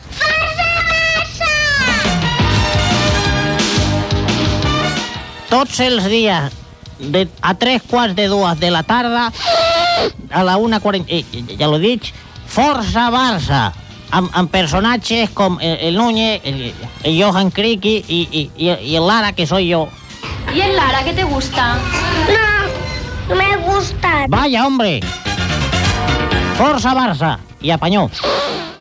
Promoció del programa, feta per Sergi Mas, imitant a l'empresari José Manuel Lara Hernández, fundador de l'Editorial Planeta.
Esportiu